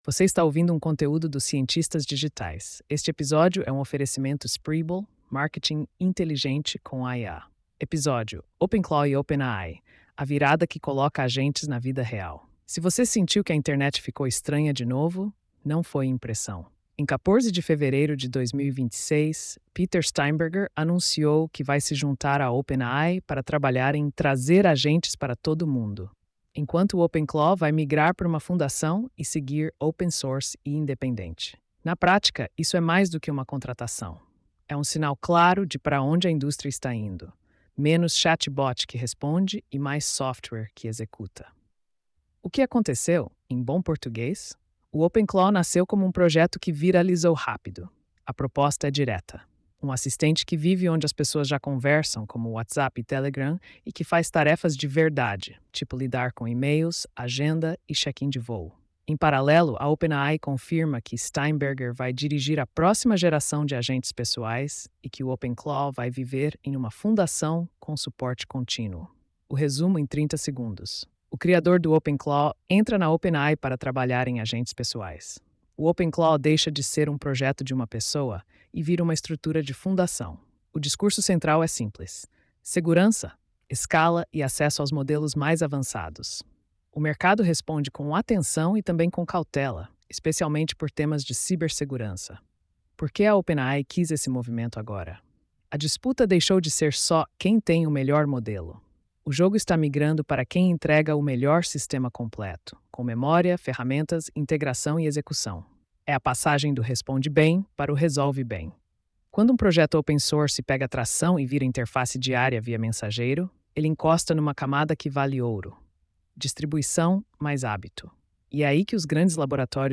post-4658-tts.mp3